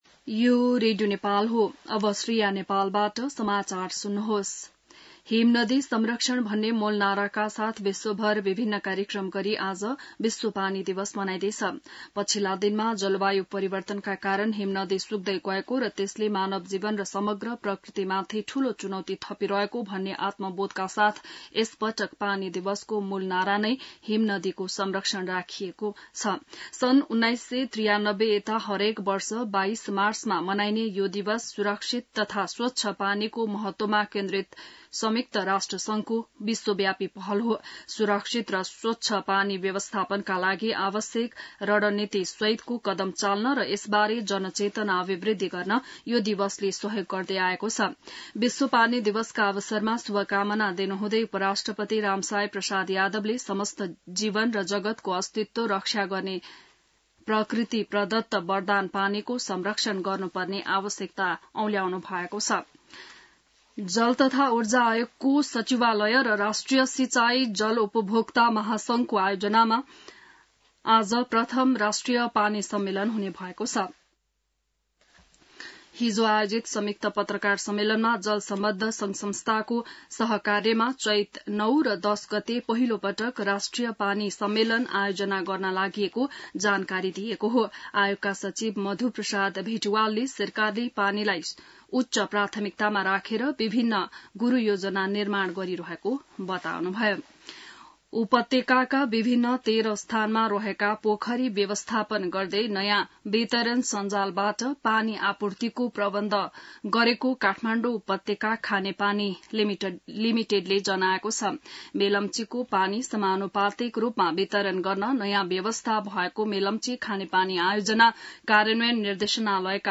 बिहान ६ बजेको नेपाली समाचार : ९ चैत , २०८१